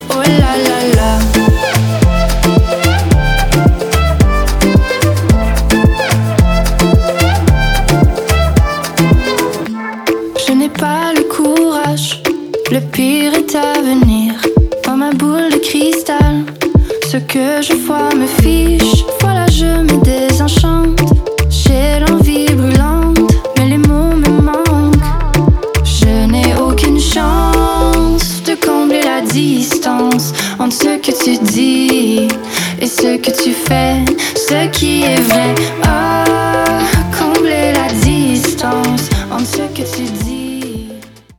• Качество: 320, Stereo
поп
dance
красивый женский голос
исполняющая в стиле электро-поп.